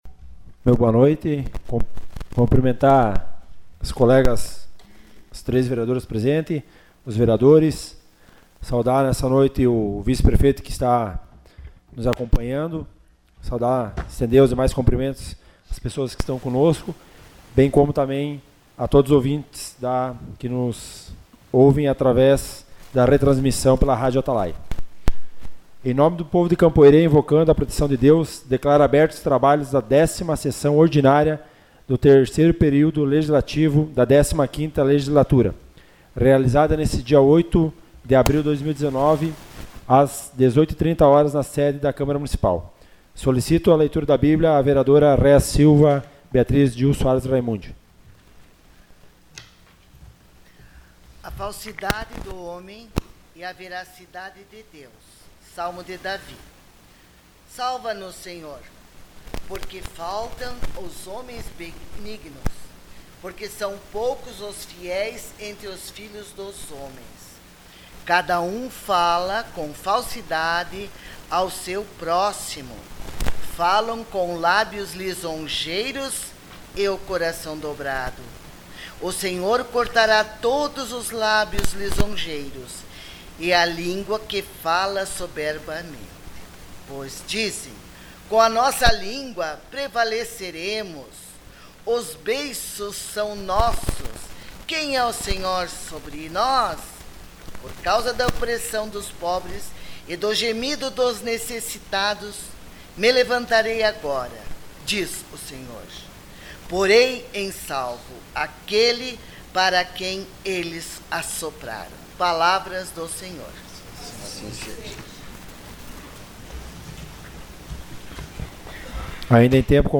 Sessão Ordinária dia 08 de abril de 2019.
Gravação das Sessões